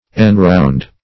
\En*round"\
enround.mp3